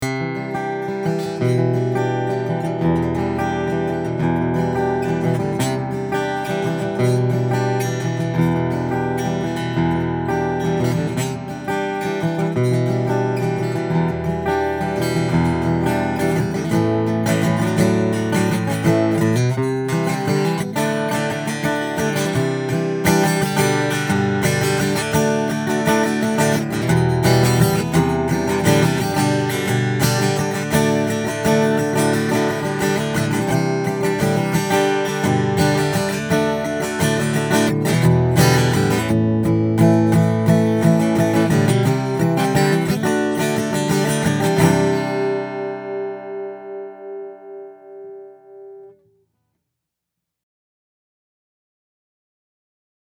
All of the clips are with the guitar plugged directly into my pre-amp going into my DAW.
I recorded the individual images with the image mix cranked all the way up.